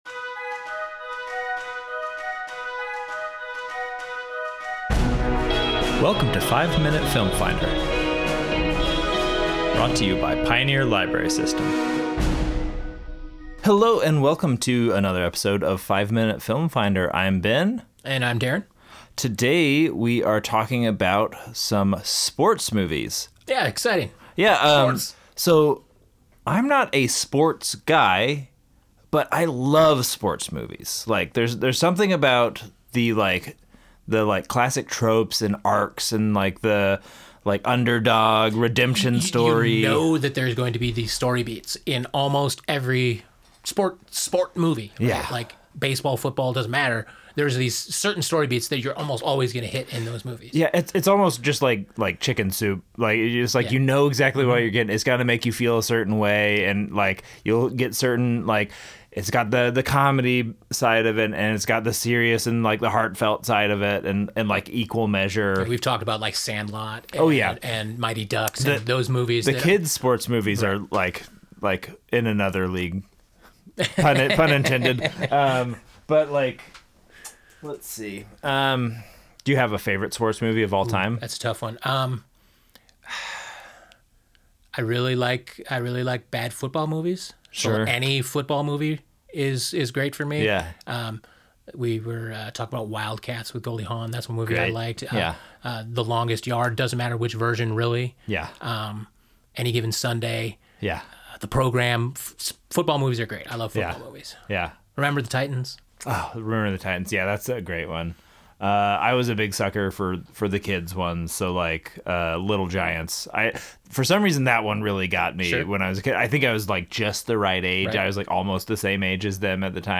Our hosts have five minutes to inform and sell you on the movies covered in this episode.